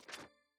map_open_lowfreq 1.wav